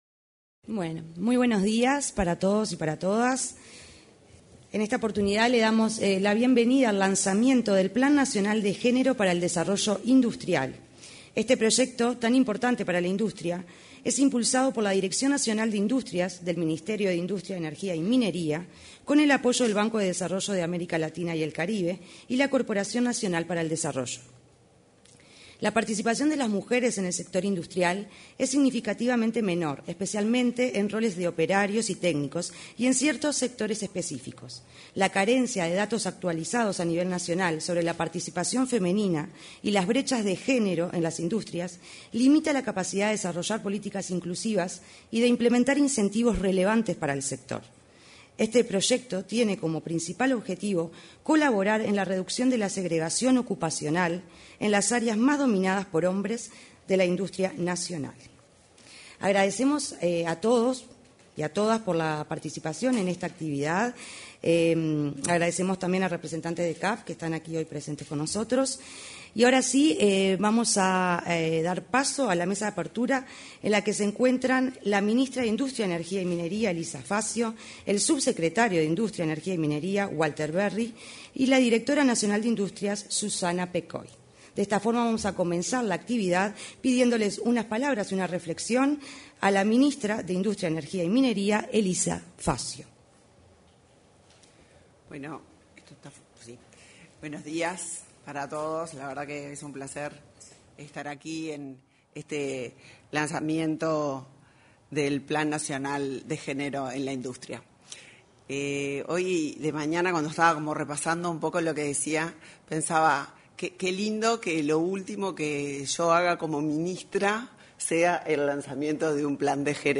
Este jueves 27, se realizó, en el salón de actos de la Torre Ejecutiva, el lanzamiento del Plan Nacional de Género para el Desarrollo Industrial.